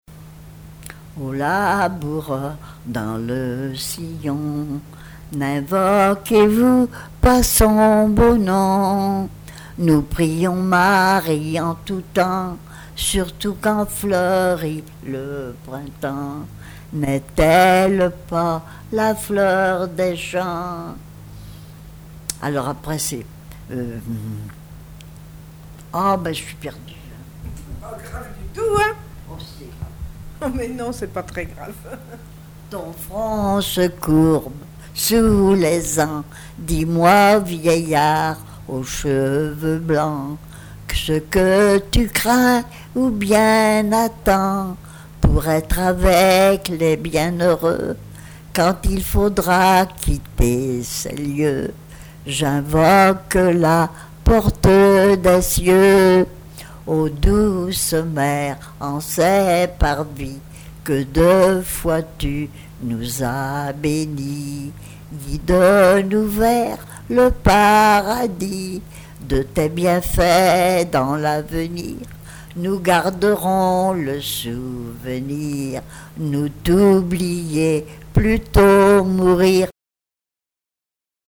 Mémoires et Patrimoines vivants - RaddO est une base de données d'archives iconographiques et sonores.
sans doute un cantique
Pièce musicale inédite